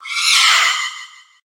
Cri de Fantyrm dans Pokémon HOME.